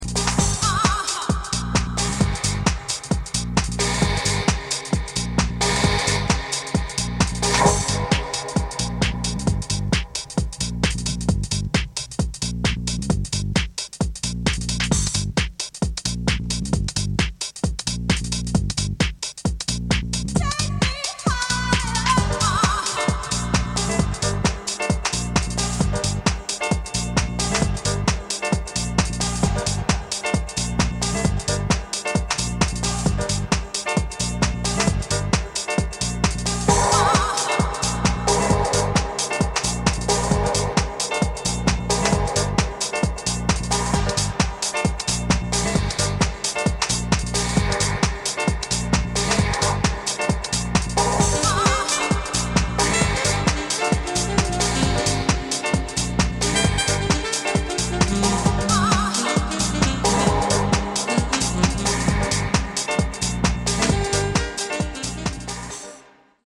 Debut album from Tech-House supremo